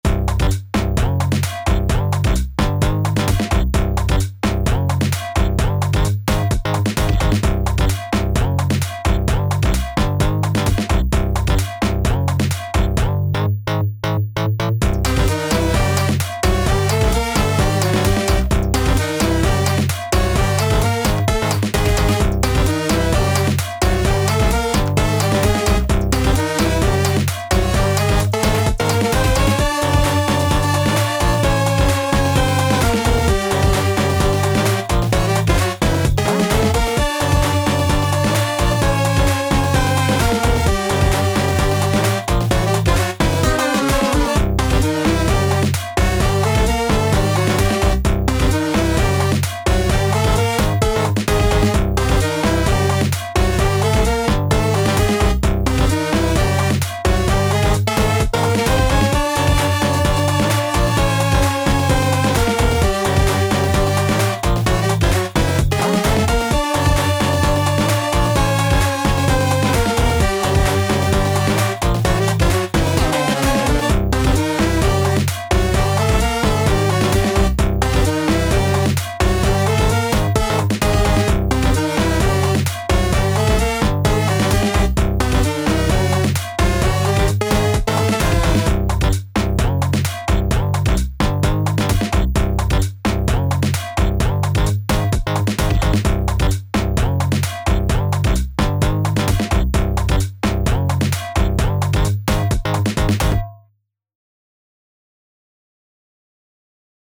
タグ: Beat コミカル 不気味/奇妙 変わり種 電子音楽 コメント: 奇妙でノリが良い電子音楽。